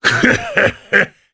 Ricardo-Vox_Happy1_jp.wav